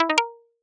Notification Ding
Ding Message Notification Phone Ring Text sound effect free sound royalty free Sound Effects